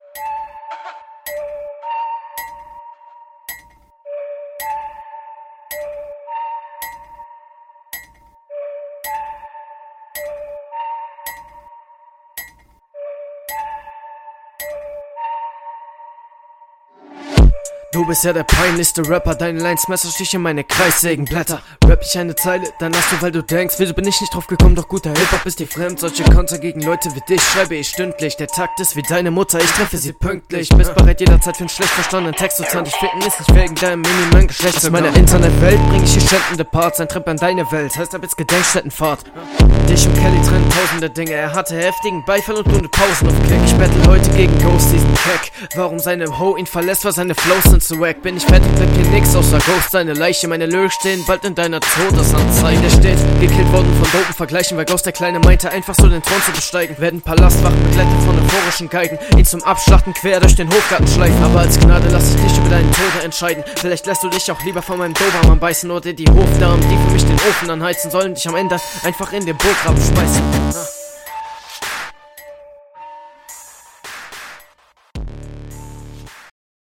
Auditiv wieder eine Zumutung was du hier hochlädst :D Du hättes eindeutig mehr Cuts machen …